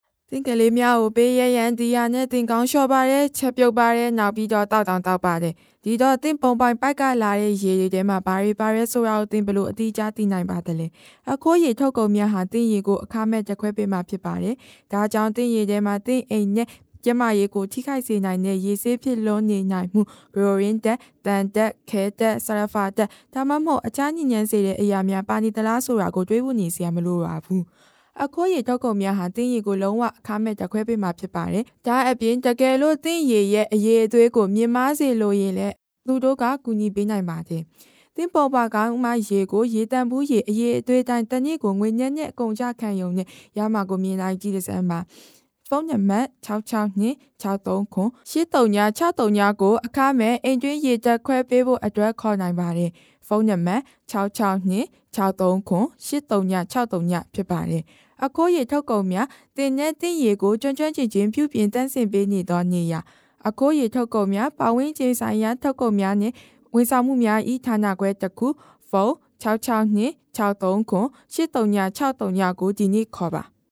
Voiceover Artists
EQHO provides multi-language solutions from its in-house recording facilities
Burmese Female 03969
NARRATION
COMMERCIAL